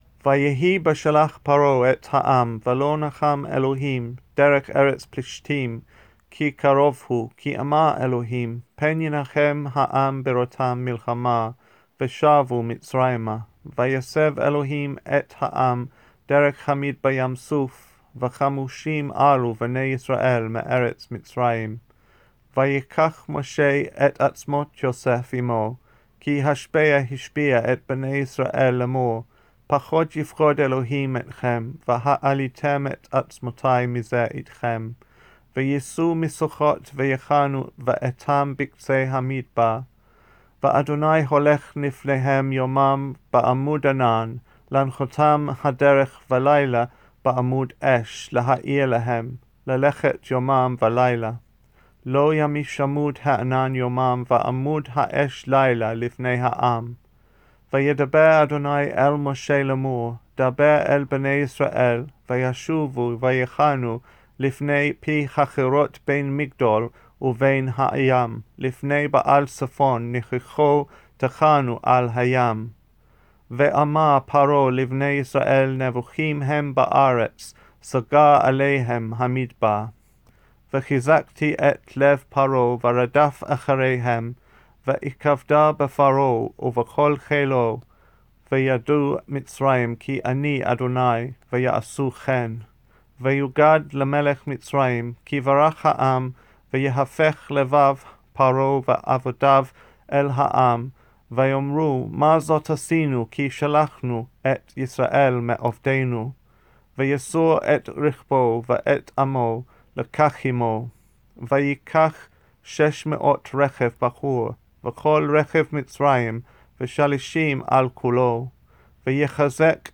Weekly Torah Readings